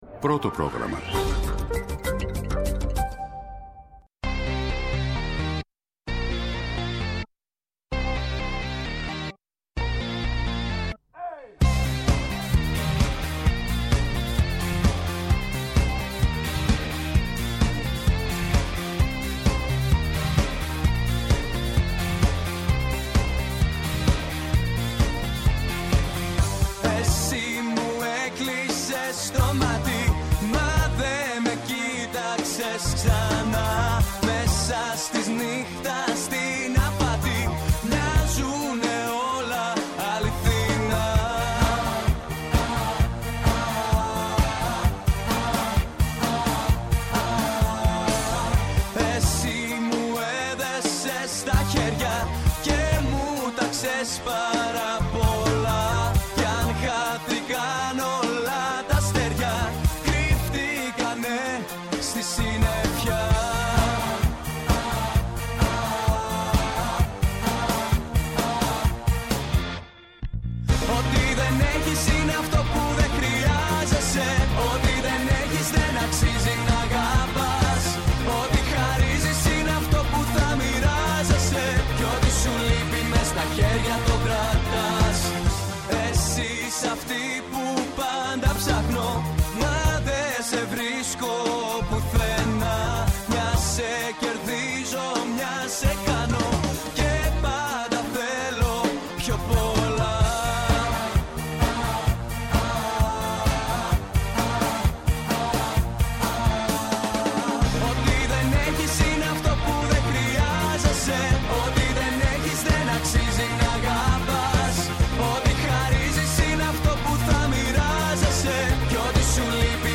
Τα θέματα που μας απασχόλησαν, μέσα από ηχητικά αποσπάσματα, αλλά και συνεντεύξεις.